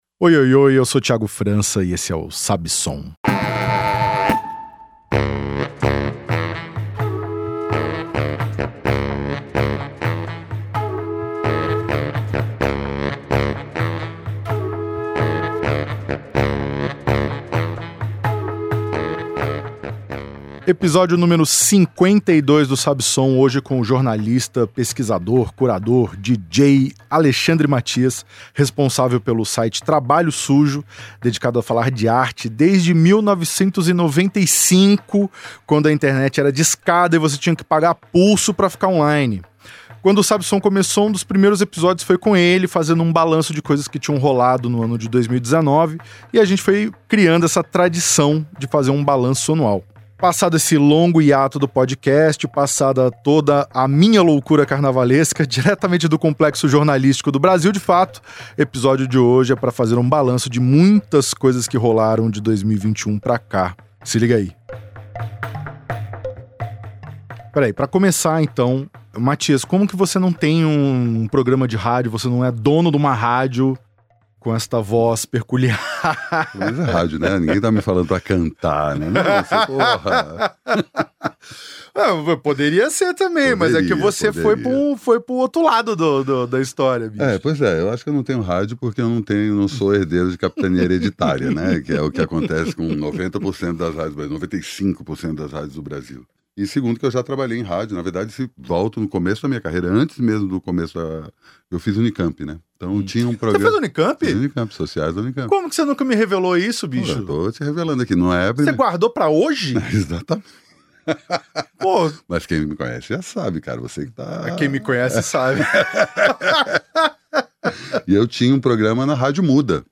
‘Quem paga, aparece mais’, diz DJ e pesquisador sobre lógica do streaming